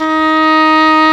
Index of /90_sSampleCDs/Roland LCDP04 Orchestral Winds/WND_English Horn/WND_Eng Horn 2